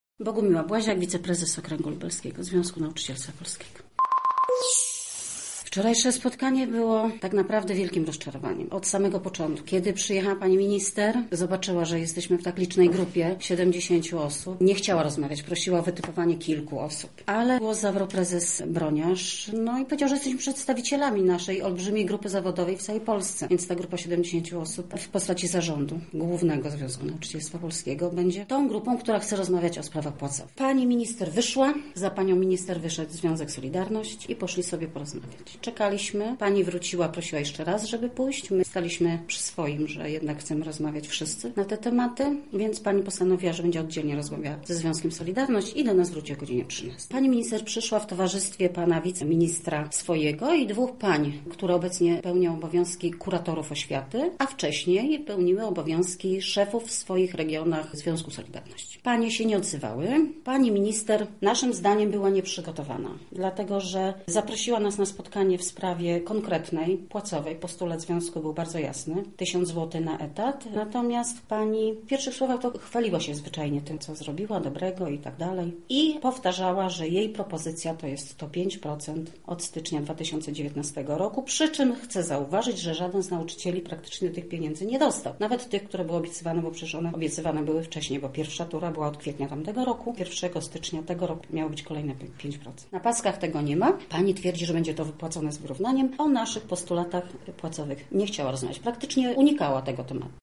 Wczoraj związkowcy ubrani w żółte kamizelki spotkali się z minister Anną Zalewską. Udało nam się porozmawiać także z przedstawicielką Okręgu Lubelskiego Związku Nauczycielstwa Polskiego, która podsumowuje wczorajsze spotkanie: